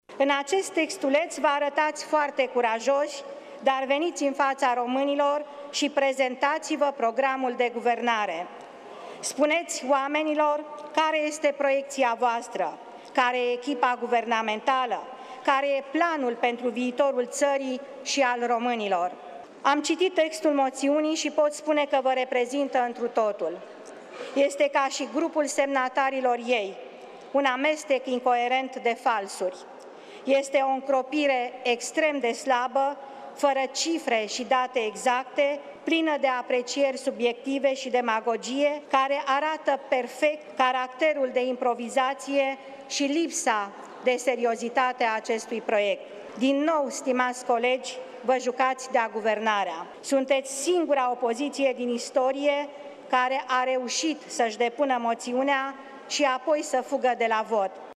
Moţiunea de cenzură intitulată ‘Ca să reconstruim România, Guvernul Dăncilă trebuie demis de urgenţă!’ a fost prezentată în plenul reunit al Parlamentului.
Moţiunea de cenzură este semnată de aceiaşi oameni amatori, iresponsabili, care vor să demoleze Guvernul fără să vină cu temele făcute, a afirmat premierul Viorica Dăncilă: